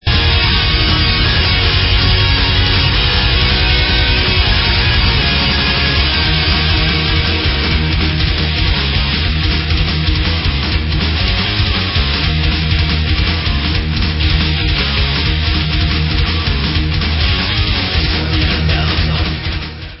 CZECH FEMALE FRONTED POWER METAL BAND